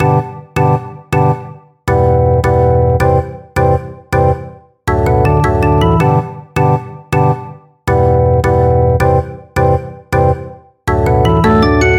嘻哈风琴
描述：欢快的嘻哈